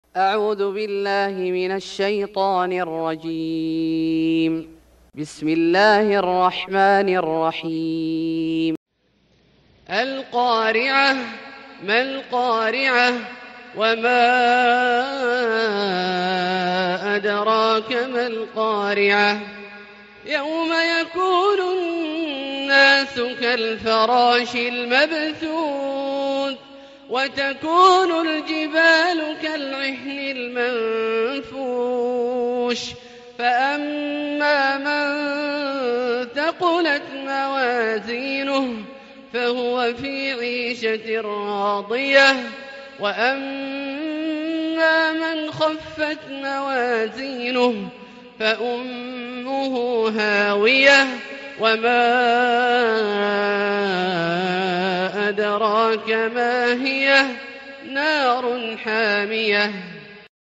سورة القارعة Surat Al-Qariah > مصحف الشيخ عبدالله الجهني من الحرم المكي > المصحف - تلاوات الحرمين